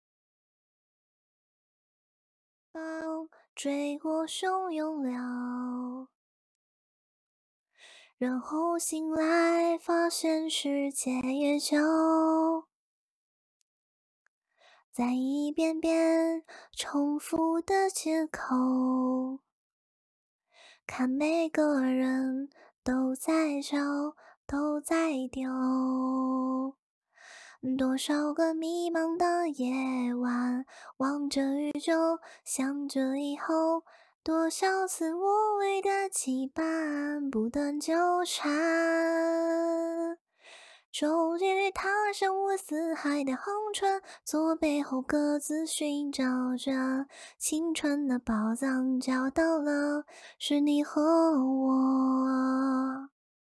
AI 精品女声 芸芸
芸芸，精品女声模型，小夹子，适合唱歌，女转女。